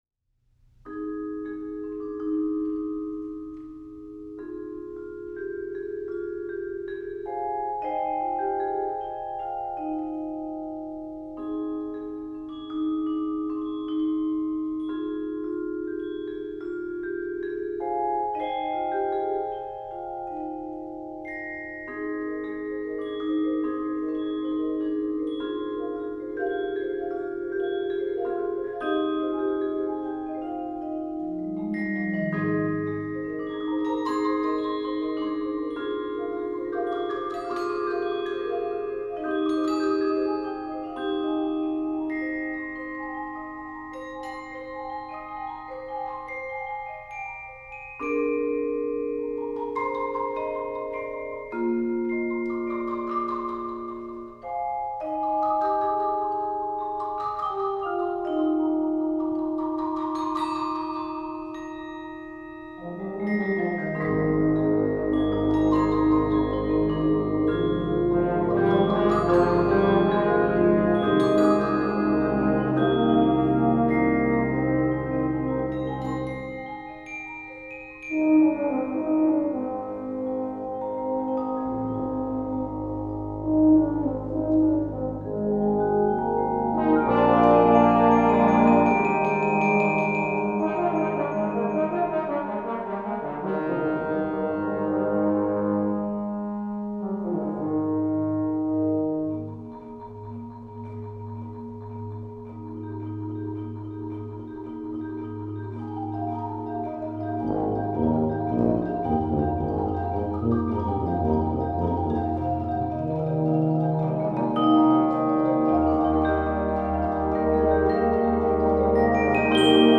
Voicing: 15 Percussion